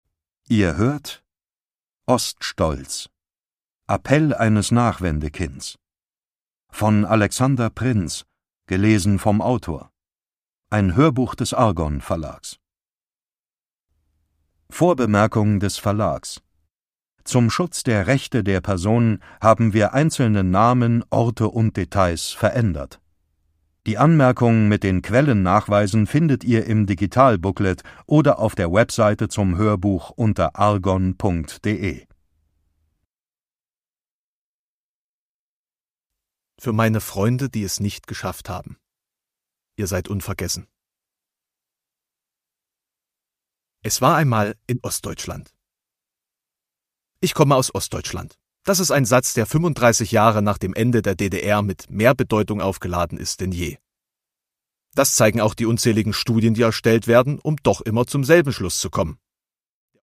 Produkttyp: Hörbuch-Download
Gelesen von: Alexander Prinz
Das Hörbuch wird gelesen vom Autor.